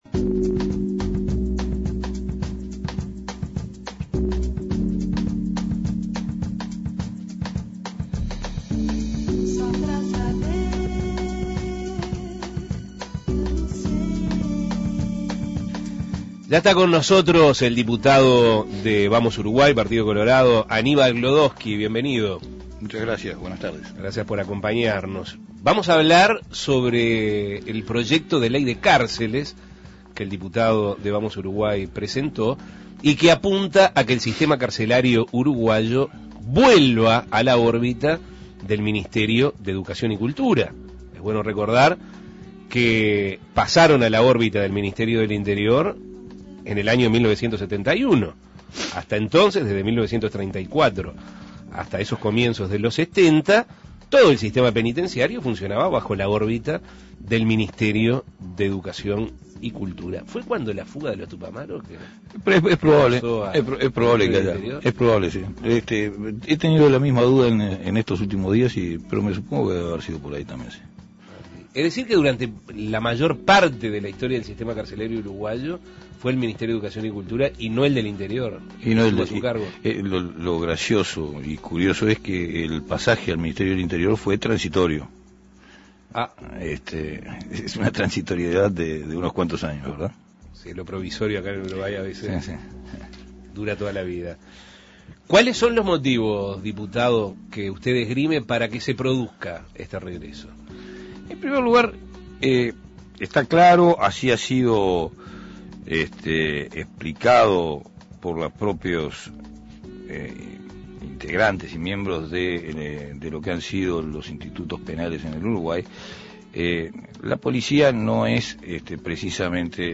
El diputado por Vamos Uruguay Aníbal Gloodtdofsky dialogó sobre este proyecto, que apunta a que el sistema carcelario uruguayo vuelva a la órbita del Ministerio de Educación y Cultura, creando el Instituto Nacional Penitenciario y Carcelario, y haciendo especial énfasis en un modelo de reclusión moderno, que mejore las condiciones de reclusión, descongestione el hacinamiento carcelario y combine programas de rehabilitación y de reinserción laboral y social de los reclusos. Escuche la entrevista.